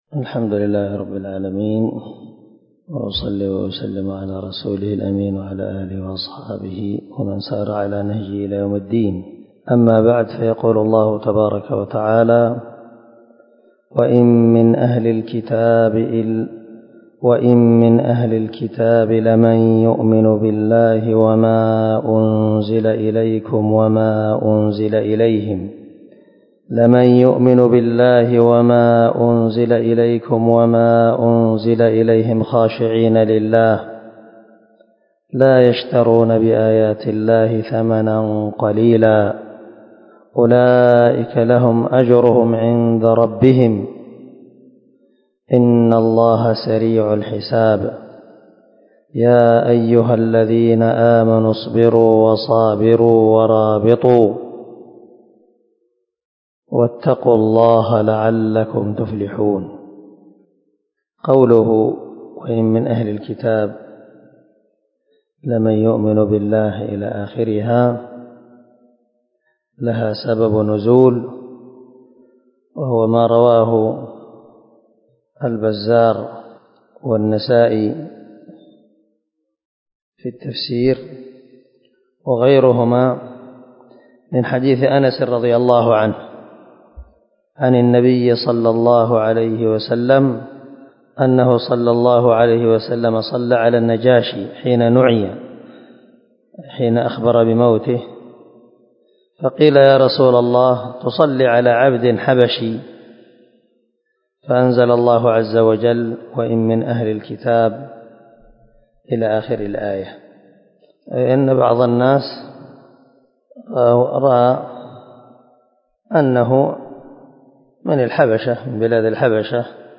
232الدرس 77 تفسير آية ( 199 - 200 ) من سورة آل عمران من تفسير القران الكريم مع قراءة لتفسير السعدي